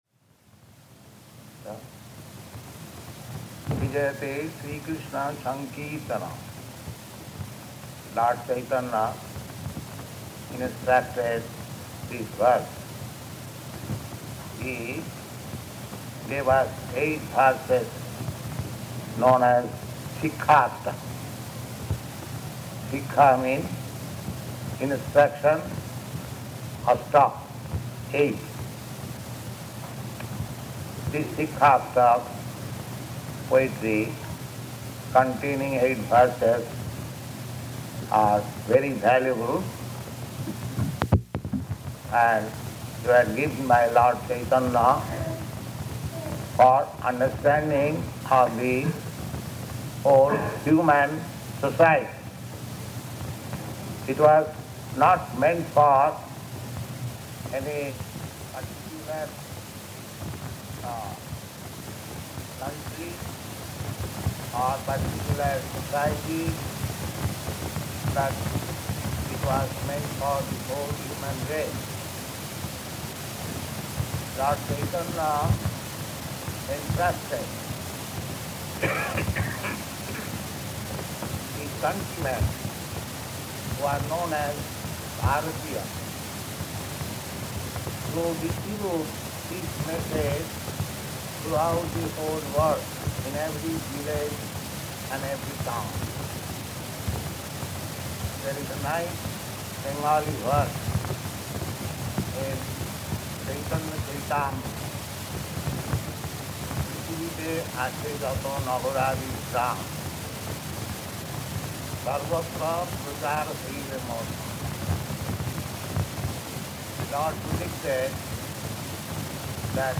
Speech to Indian Audience
Speech to Indian Audience --:-- --:-- Type: Lectures and Addresses Dated: July 28th 1968 Location: Montreal Audio file: 680728SP-MONTREAL.mp3 Prabhupāda: So?